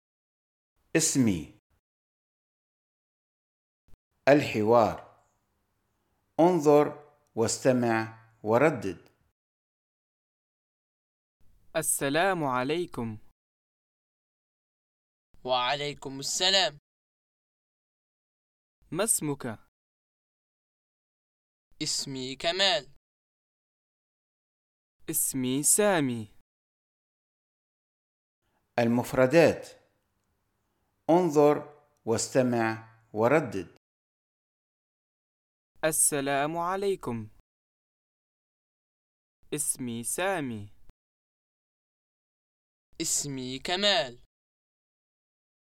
• Enregistrement audio de tout le contenu du manuel de l’élève
Jaime-1-Audio-lecon-1_Extrait.mp3